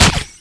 fire_pulse3.wav